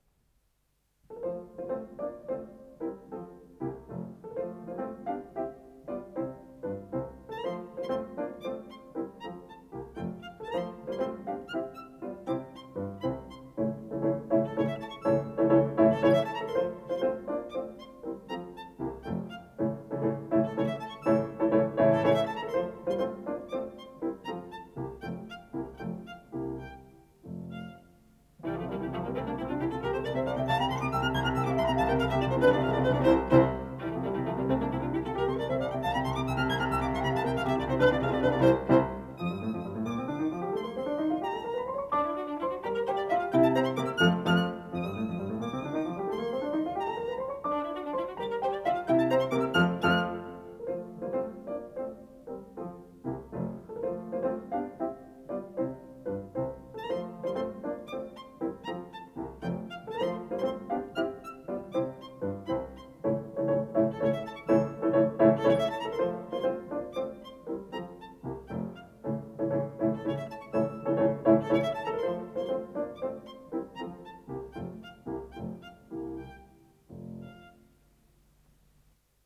Violin Sonata